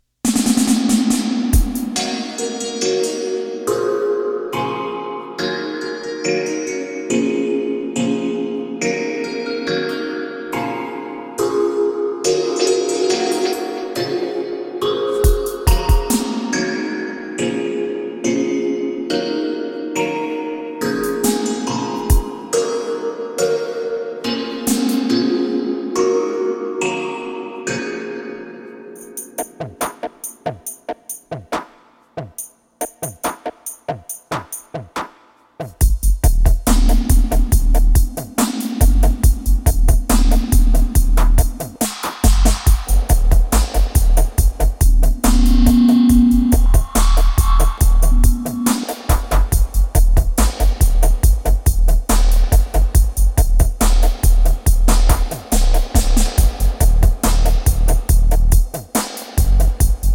produced and mixed solely on analogue gear.
Both tracks are full of heavy and deep vibrations!
Side B2: Dub Mix